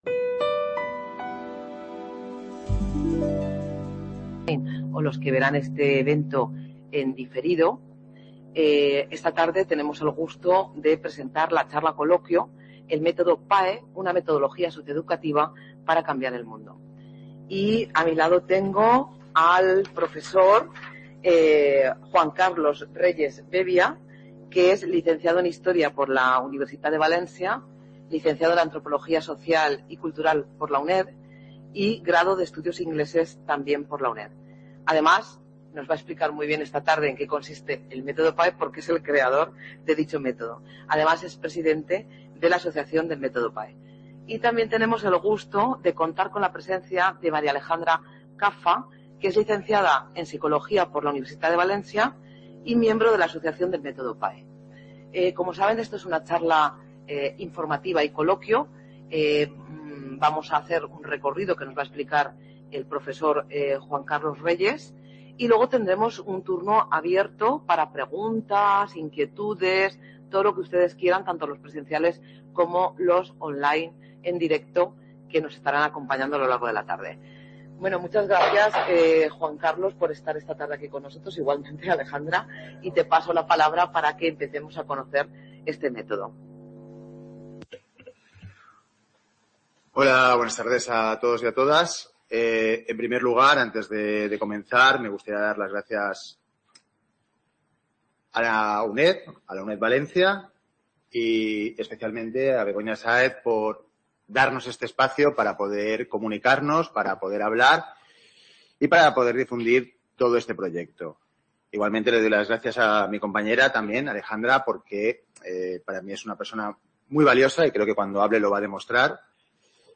En esta charla-coloquio podremos conocer las principales pautas del Método PAEC (Método Peripatético Adaptado a la Educación Contemporánea) en la educación reglada desde Primaria hasta Posgrado, así como fuera del ámbito educacional mediante el trabajo en el ámbito social con algunas ONG. Hablaremos de sus datos, que se tratan de manera científica y que arrojan unos resultados muy positivos, pero, sobre todo, se mostrará a través de dos pequeñas historias personales la transformación social que este método implica.